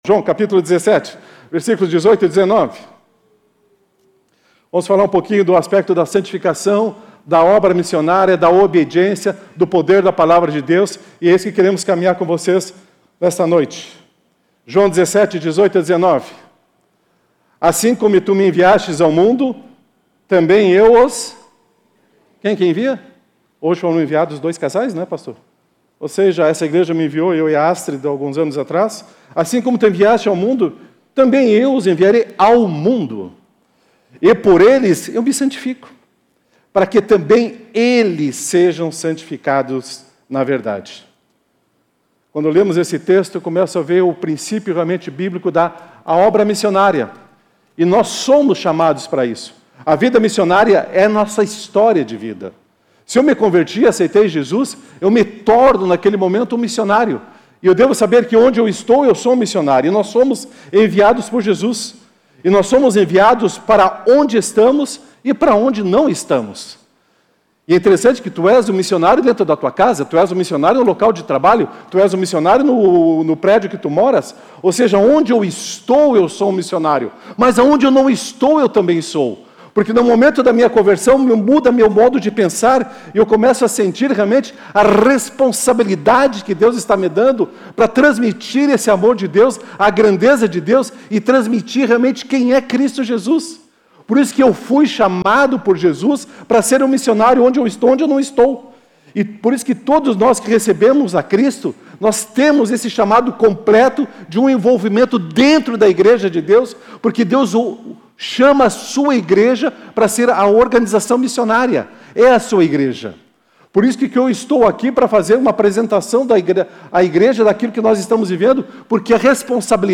Mensagem apresentada
na Igreja Batista do Bacacheri.